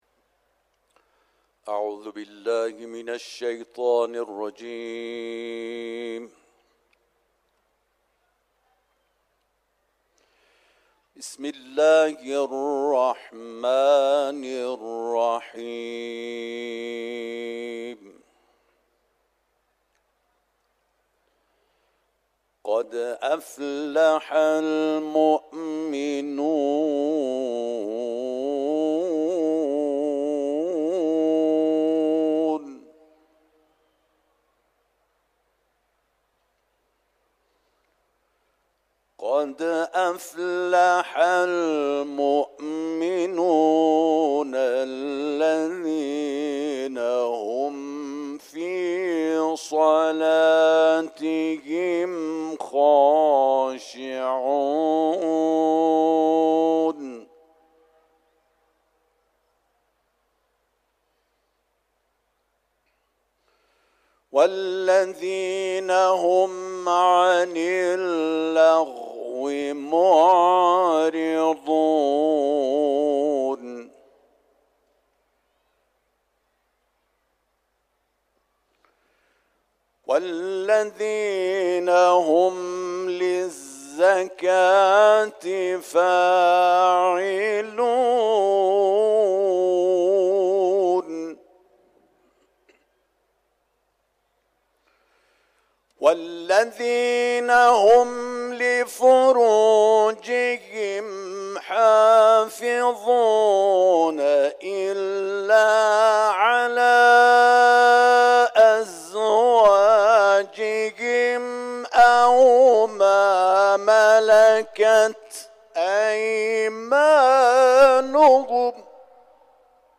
صوت تلاوت آیات ابتدایی سوره‌ «مومنون»